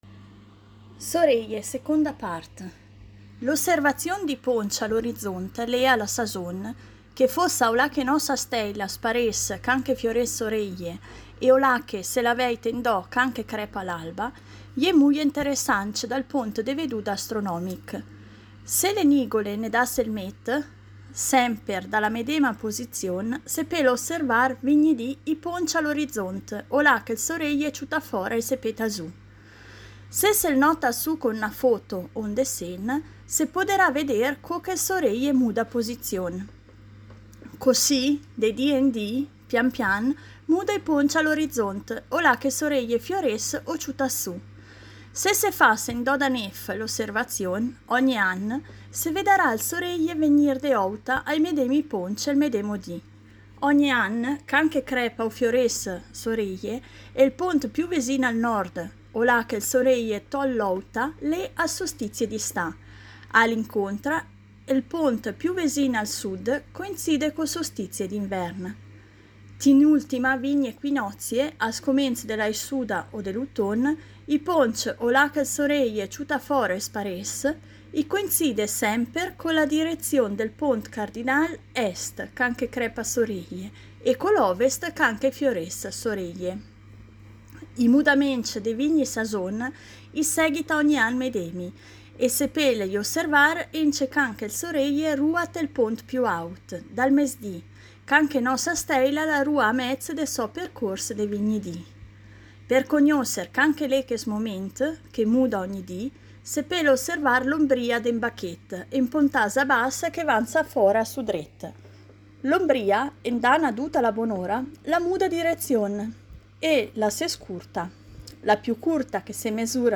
Il progetto “Idiomi celesti” promuove l’osservazione ad occhio nudo del cielo stellato con testi scritti e letti ad alta voce in tutte le lingue, dialetti compresi, come ad esempio la lingua ladina e il dialetto lumezzanese.